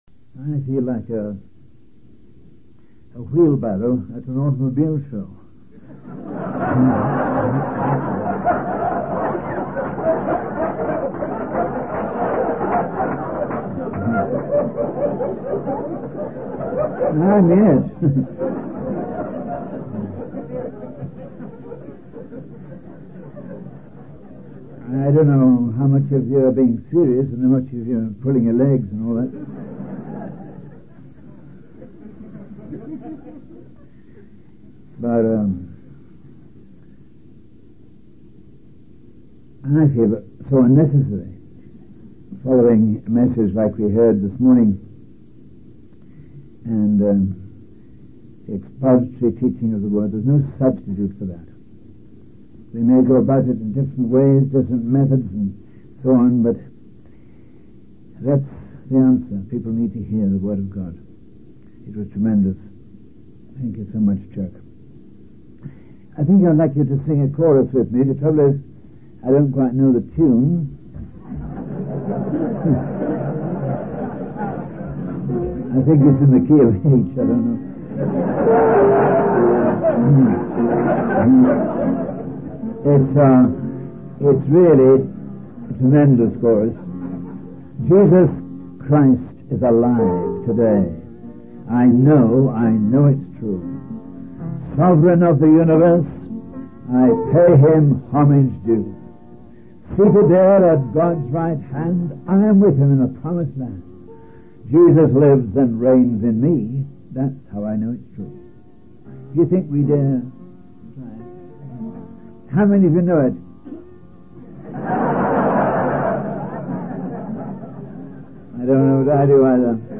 In this sermon, the speaker emphasizes the importance of dwelling with the king for his work rather than dwelling with the work for the king. He encourages listeners to rely on the sufficiency of Jesus in their daily lives. The speaker also emphasizes the need to take care of one's body, including practicing self-discipline in areas such as eating and exercise.